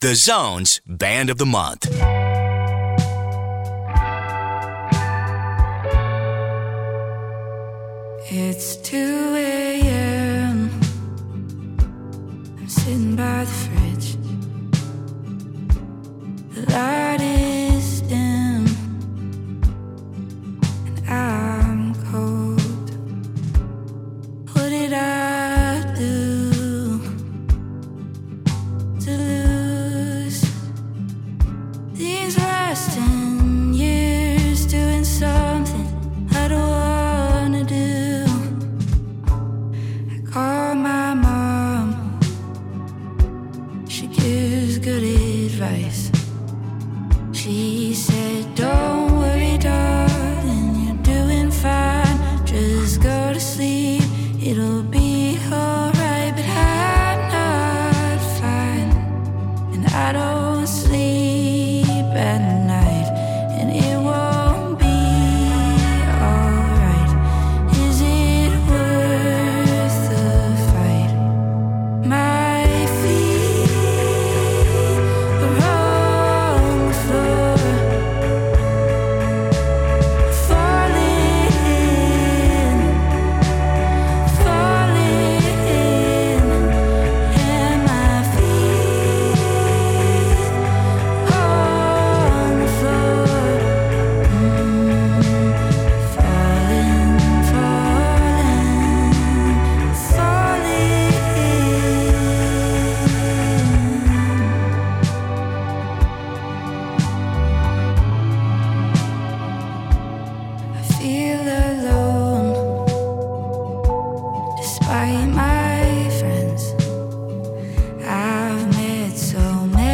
vocals, guitar
guitar, bass, synth
violin, piano
indie
and hope through relatable lyrics and emotive soundscapes.